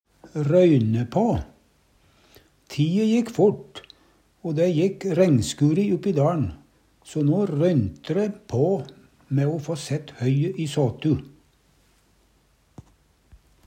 røyne på - Numedalsmål (en-US)